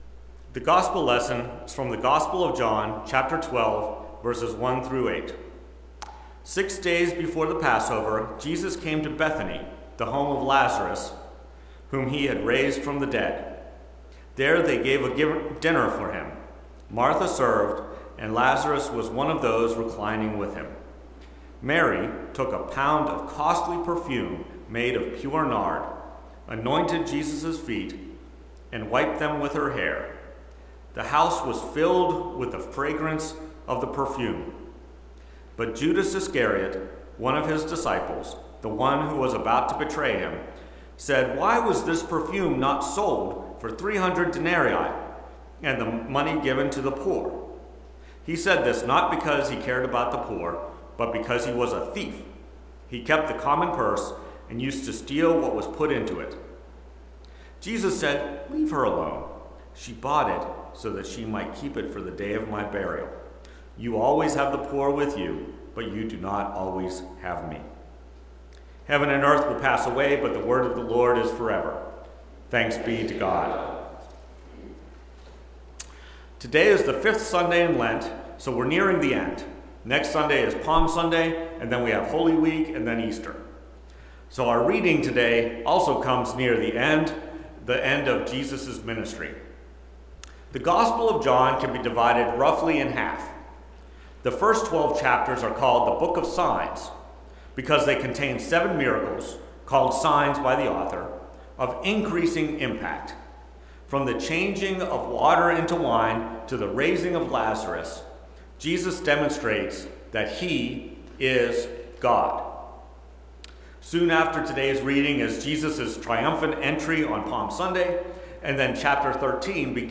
Preached at First Presbyterian Church of Rolla on April 6, 2025, Fifth Sunday in Lent. Based on John 12:1-8.